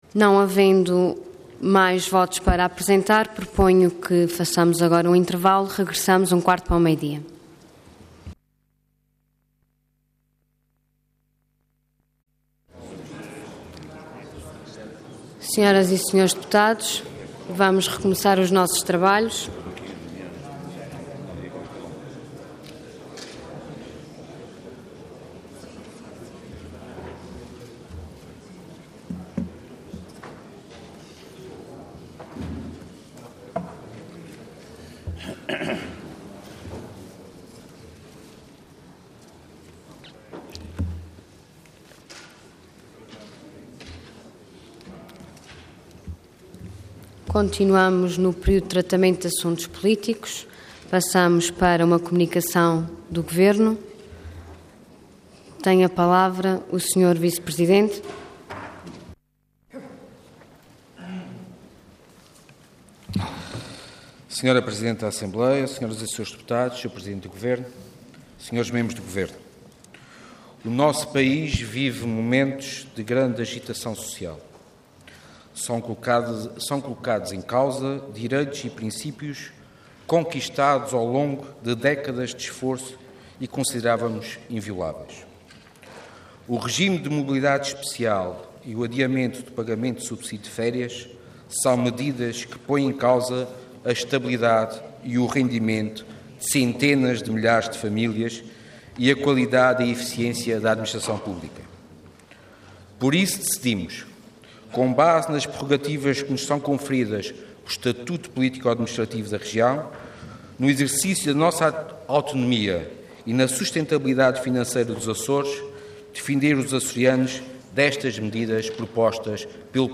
Intervenção Comunicação do Governo Orador Sérgio Ávila Cargo Vice-Presidente do Governo Regional Entidade Governo